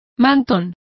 Complete with pronunciation of the translation of shawl.